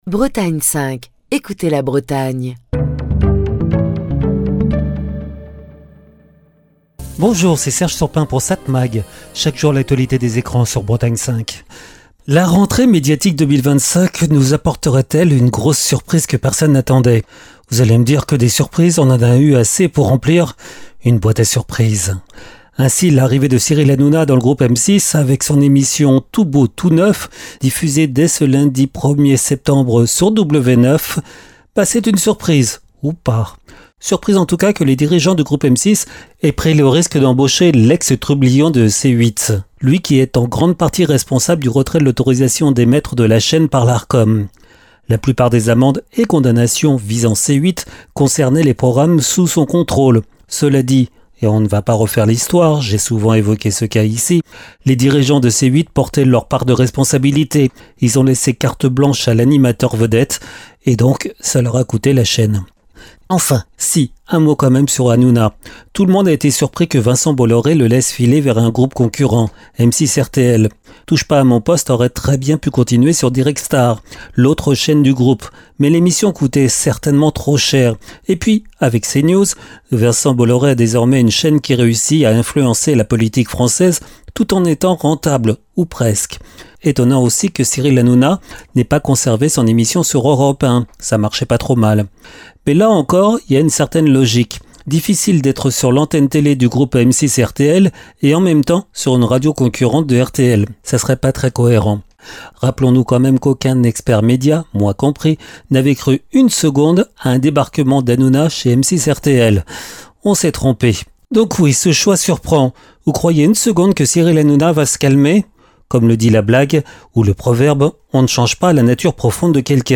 Chronique du 1er septembre 2025.